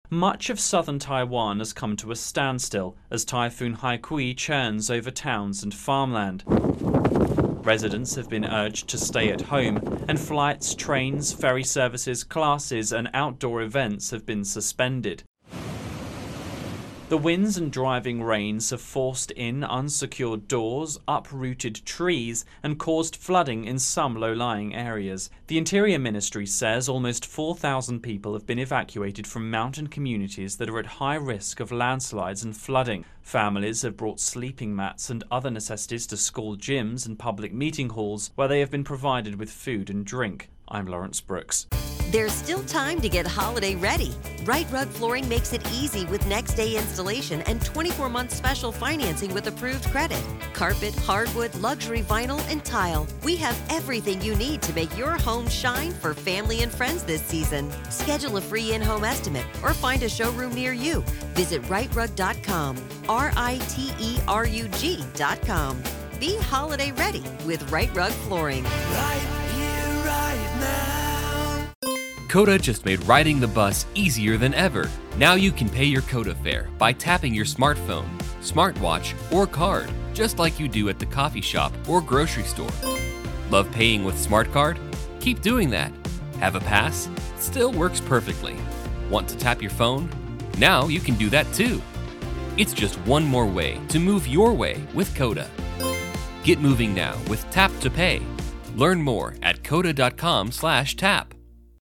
reports on Asia Typhoon Haikui.